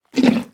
minecraft / sounds / entity / horse / eat1.ogg
eat1.ogg